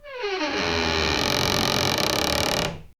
Longest 0:03 Um som de peido longo 0:15 Slow door creak open (long, resonant) 0:03
slow-door-creak-open-long-eqe6cbo5.wav